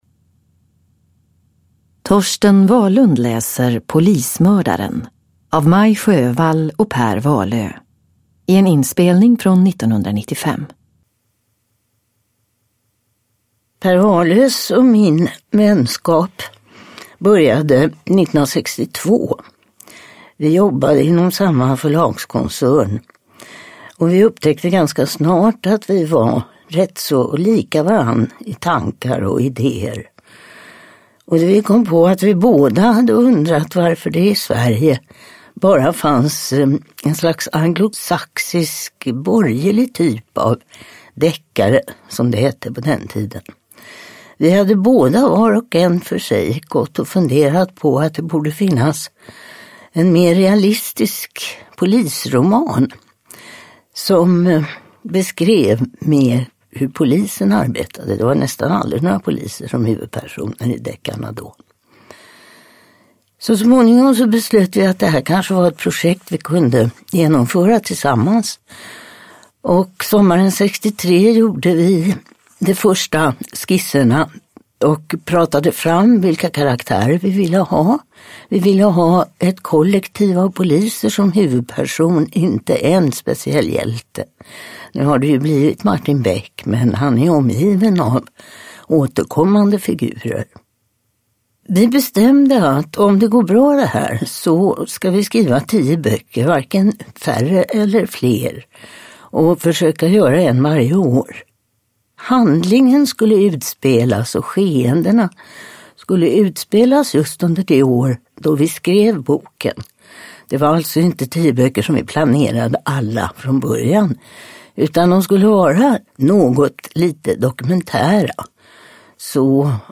Polismördaren – Ljudbok
Uppläsare: Torsten Wahlund